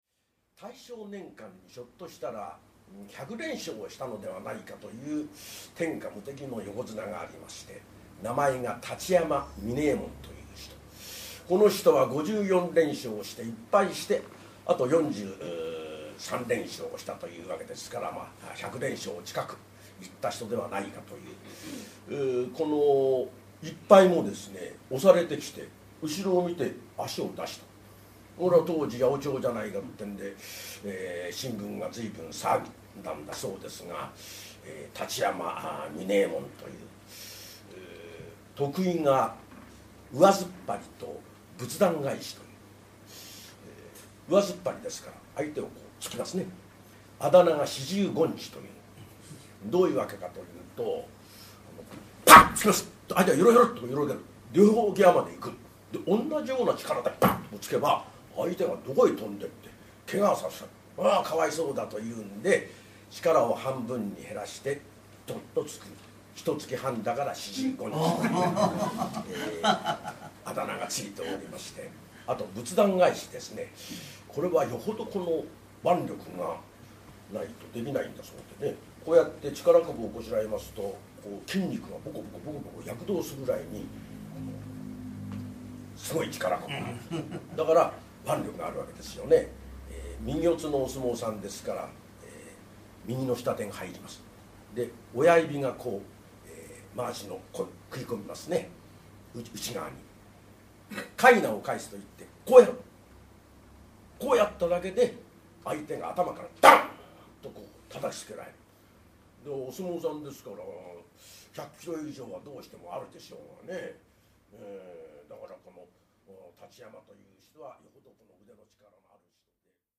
その両国寄席より、三遊亭円楽一門・真打の落語家による選りすぐり高座をお届けいたします！
静かな語り口調で噺に入る様は燻銀の輝きを秘め、いかにも玄人好みといった風情。